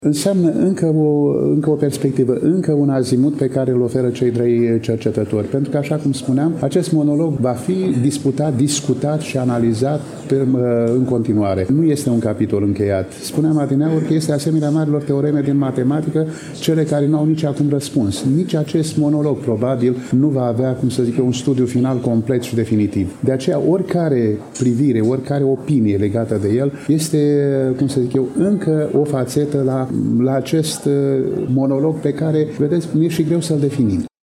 În ediția de astăzi a emisiunii noastre, relatăm de la prezentarea cărții Marele Inchizitor. Dostoievski în interpretări teologico-filosofice (Konstantin Leontiev, Vladimir Soloviov, Vasili Rozanov, Serghei Bulgakov, Nikolai Berdiaev, Dmitri Merejkovski, Semion Frank, Nikolai Losski), eveniment desfășurat în ziua de joi, 14 noiembrie 2024, începând cu ora 14,  la Iași, în incinta Librăria Tafrali, corpul A al Universității „Alexandru Ioan Cuza”.